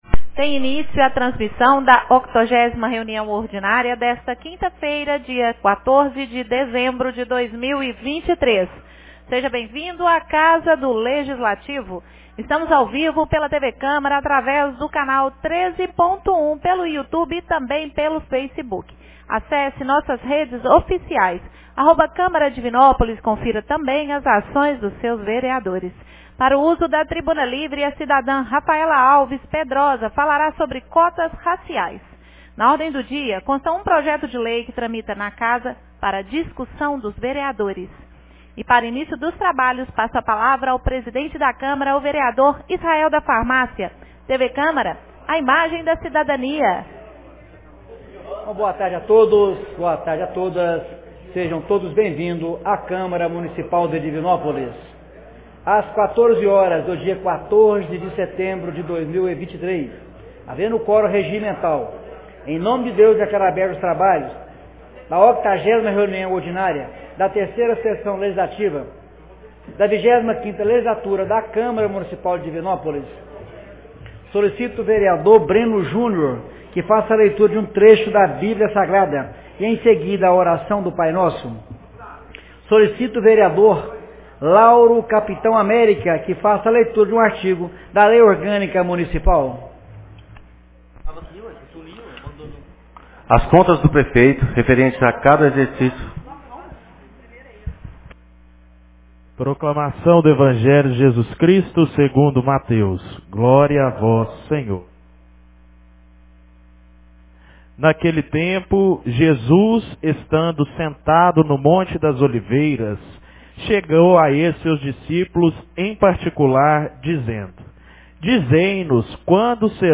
80ª Reunião Ordinária 14 de dezembro de 2023